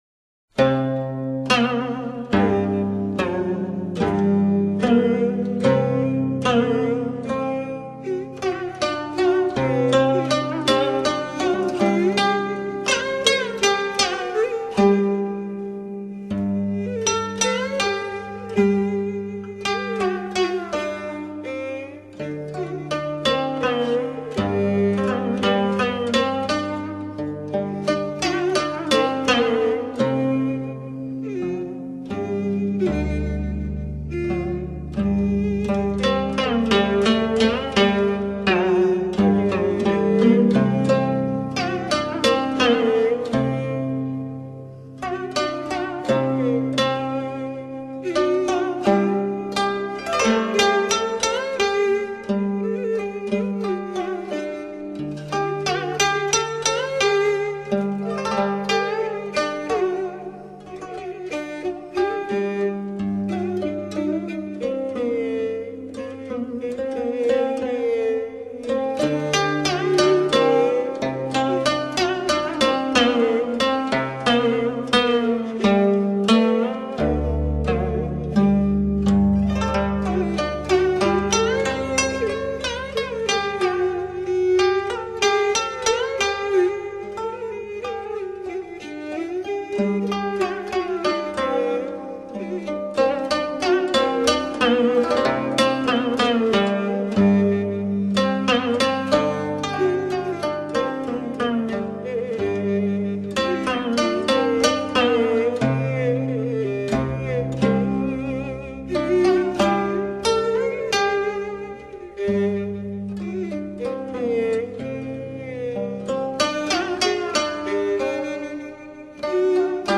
古筝被称为“民族乐器之王”，有“东方钢琴”的美誉，是中国民族乐器的代表，也是民族文化的瑰宝。